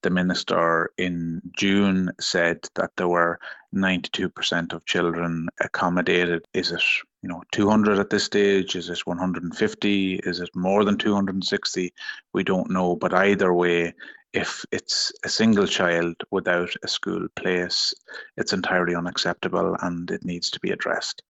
Sinn Féin Education spokesperson Darren O’Rourke says the uncertainty means parents can’t plan for the year ahead………………..